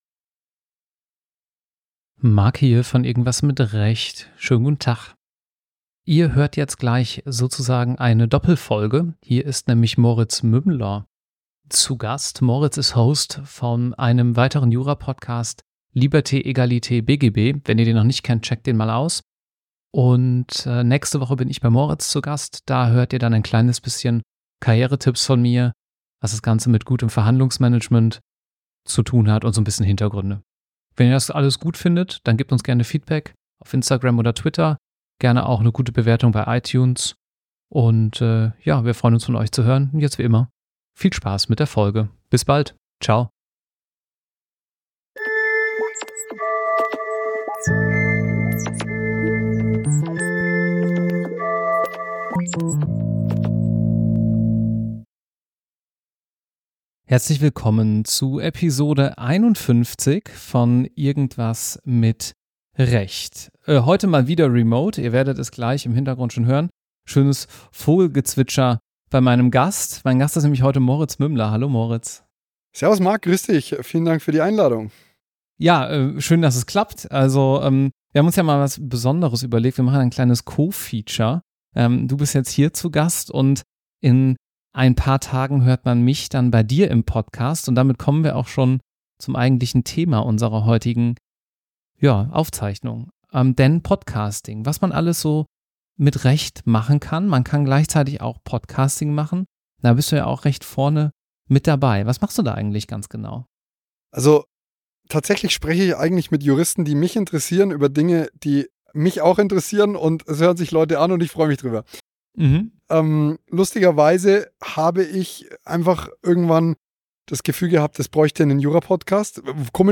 IMR051: Unternehmertum und Side Hustle | Interview Referendar ~ Irgendwas mit Recht | Vorbilder, Karrieren & Jobs im Jura-Podcast Podcast